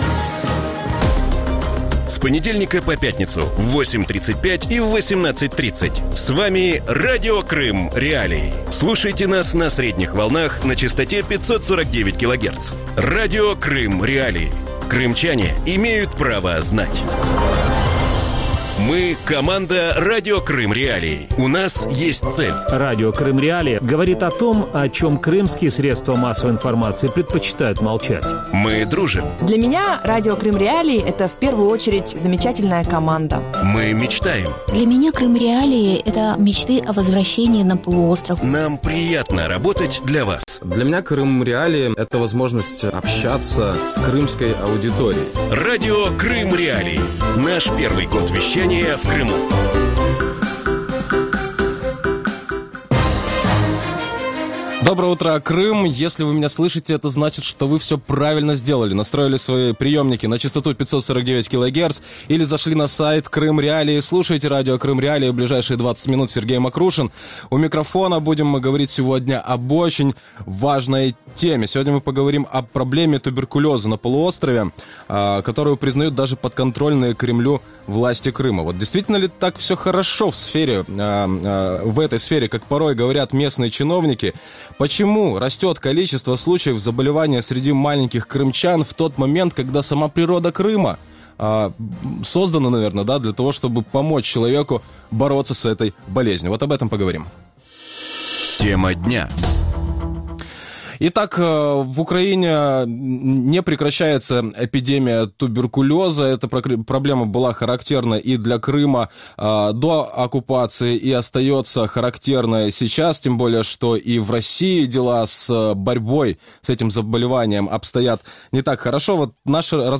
Вранці в ефірі Радіо Крим.Реалії говорять про проблему туберкульозу на півострові, яку визнає навіть підконтрольна Кремлю влада Криму.